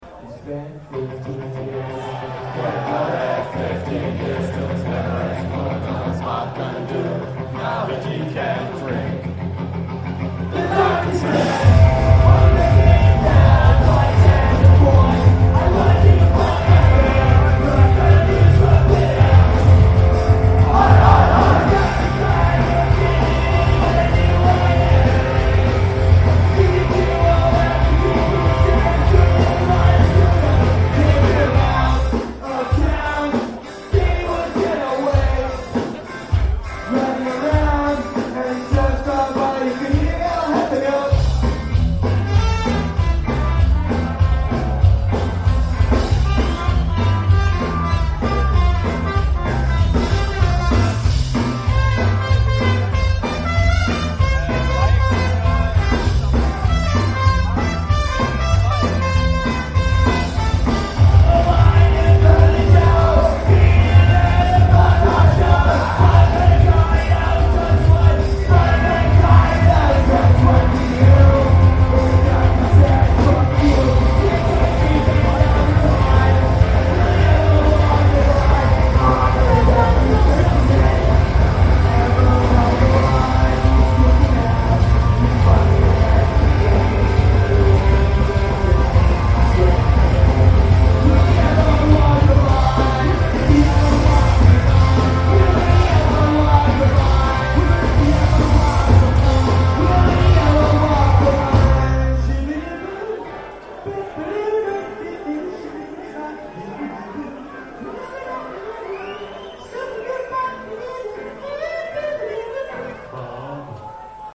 Recorder: Sharp IM-DR420H (LP4-Mode)
Microphone: Sony ECM-T6 (Mono)